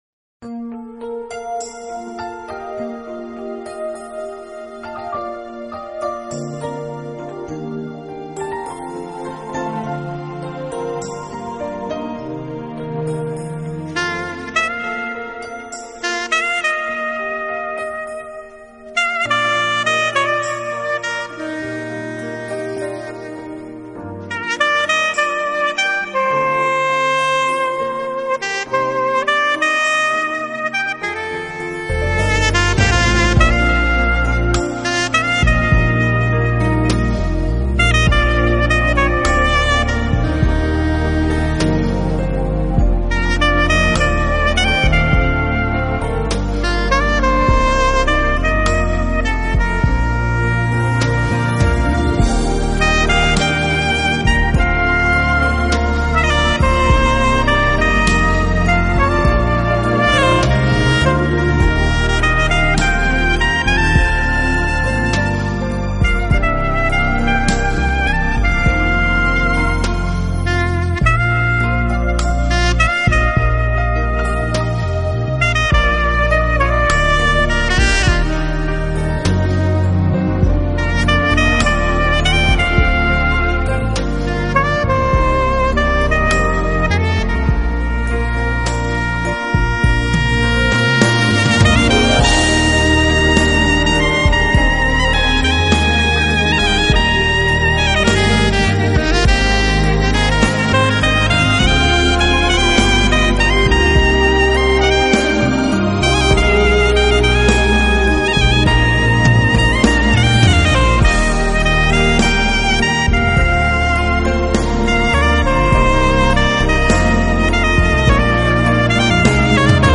心，想要休息时，你需要与一支有灵魂的萨克斯风聊聊。